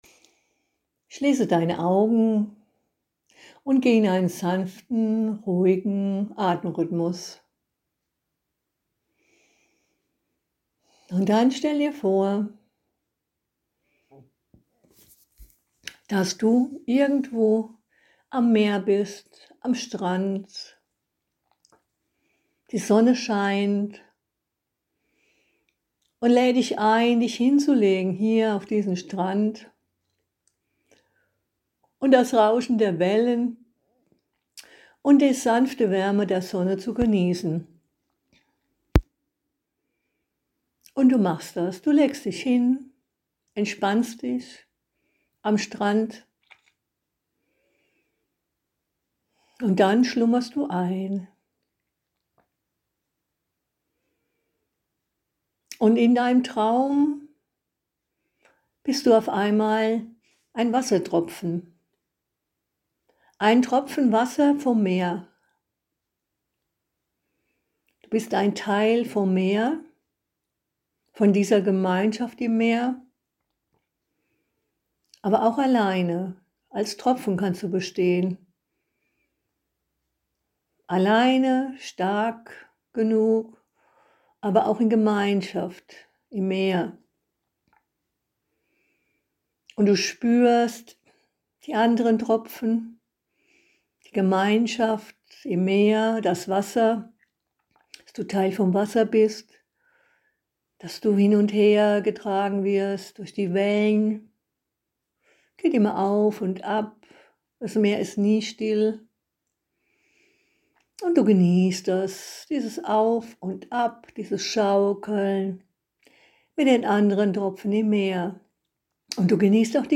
Die Meditation „Wie ein Tropfen im Meer“ lädt dich dazu ein, dich frei zu fühlen und trotzdem dazugehörig. Außerdem wirst du Leichtigkeit erfahren und den Wandel in der Natur hautnah erleben.